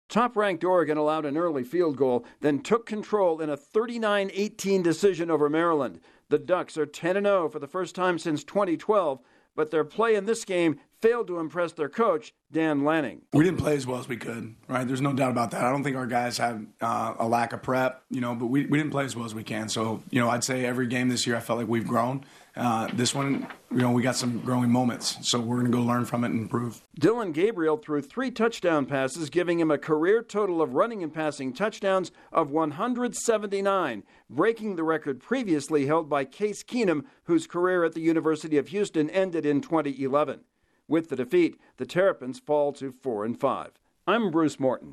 Oregon does little to harm its status as the CFP's top-ranked team. Correspondent